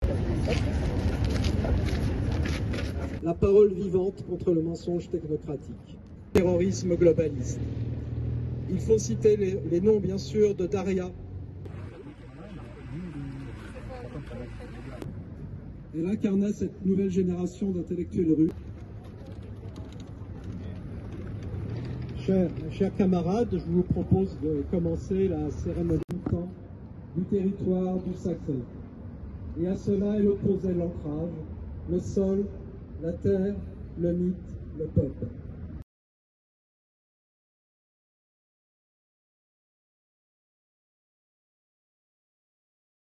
Un rassemblement à Paris en mémoire de Daria Douguina, victime du terrorisme ukrainien